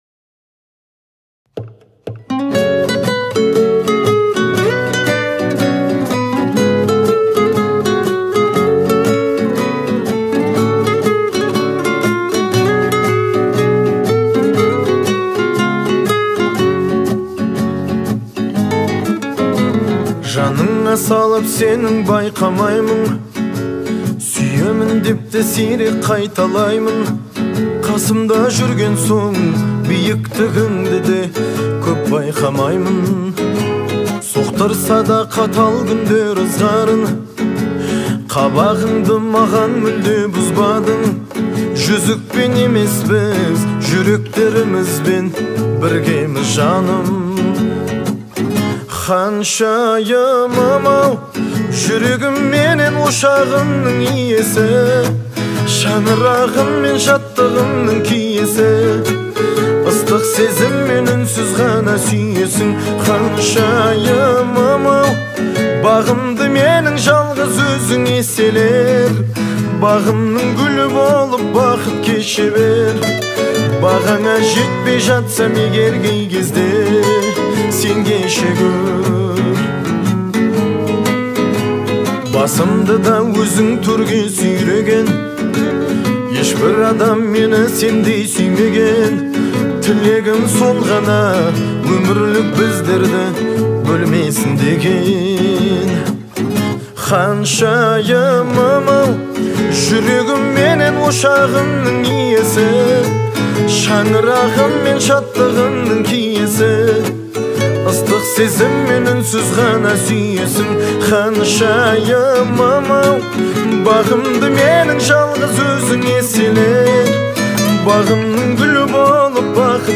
это романтическая песня в жанре казахского попа